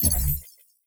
Robotic Game Notification 14.wav